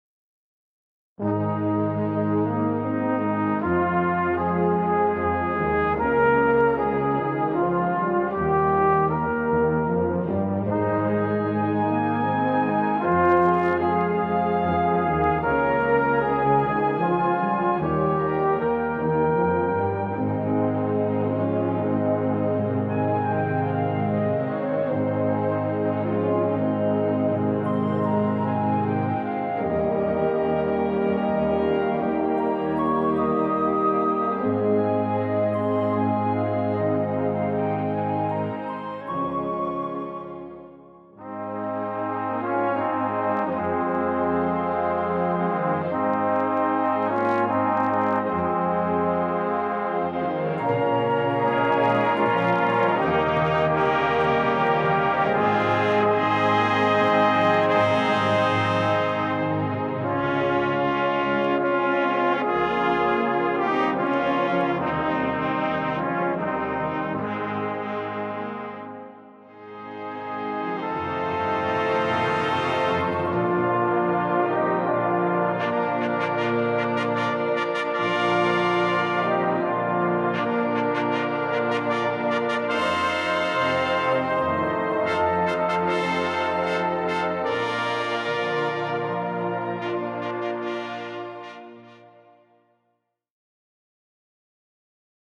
Besetzung: Blasorchester
Drei Strophen, jede Strophe in einer anderen Klangfarbe.